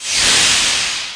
1 channel
A_STEAM.mp3